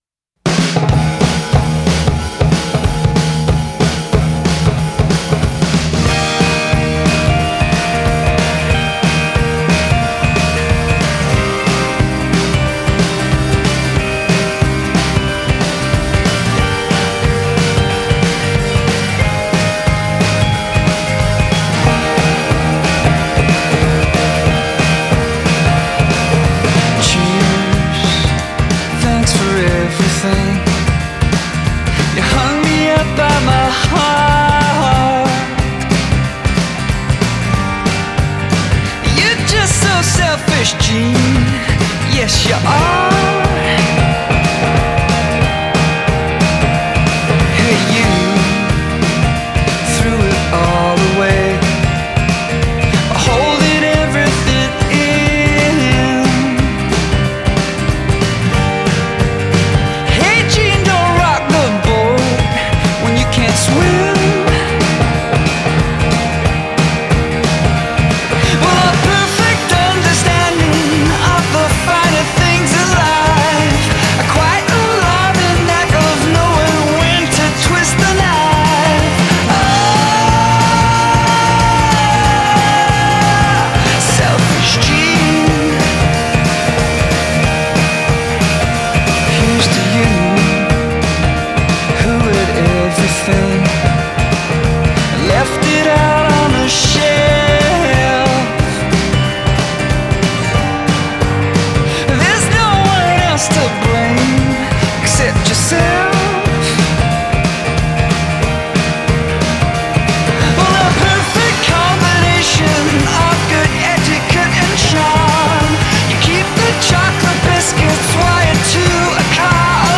Indie Indie pop Alternative rock